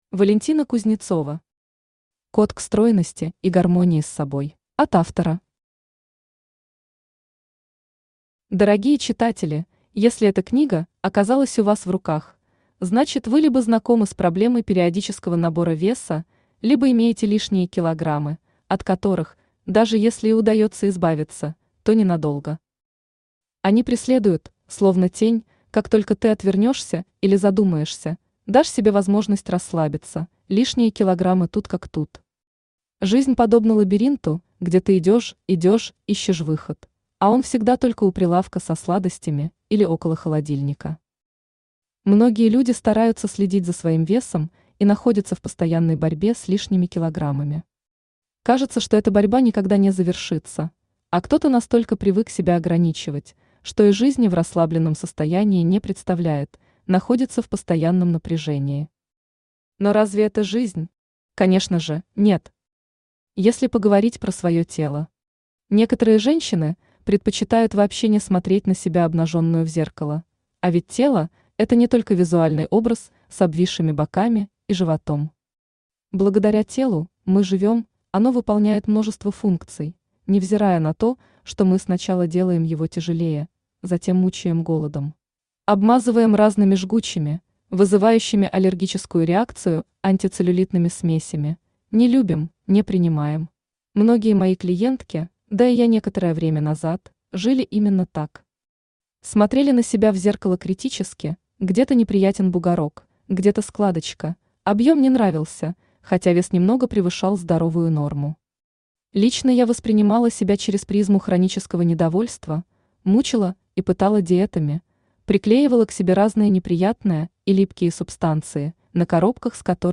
Aудиокнига Код к стройности и гармонии с собой Автор Валентина Кузнецова Читает аудиокнигу Авточтец ЛитРес.